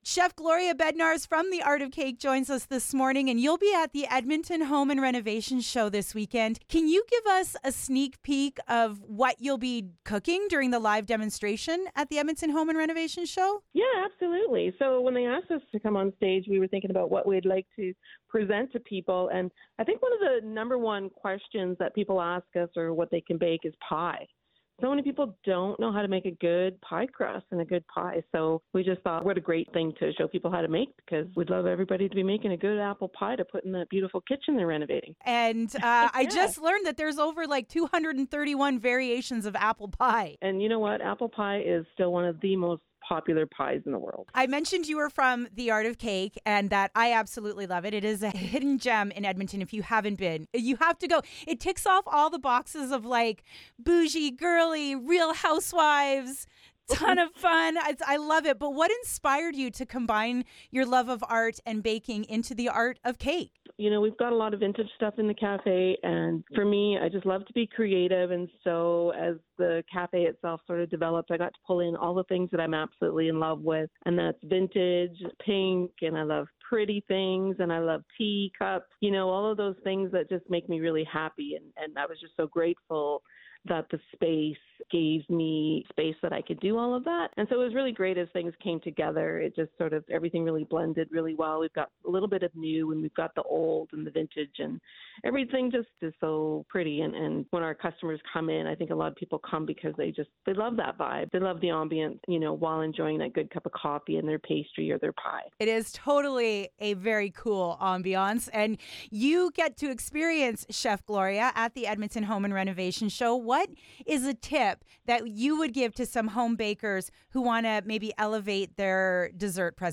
art-of-cake-website-on-air.mp3